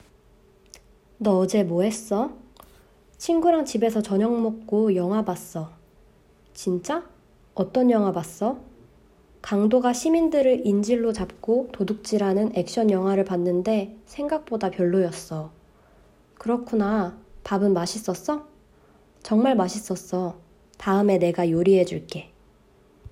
3_conversation__3_week.m4a